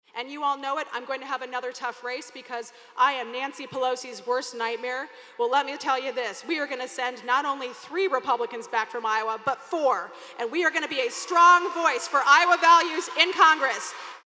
Congresswoman Ashley Hinson, a Republican from Marion, is running for re-election in the new second district. She mentioned Pelosi four times in her convention speech.